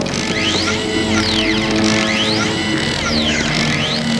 Borg sounds (90.9KB) - wav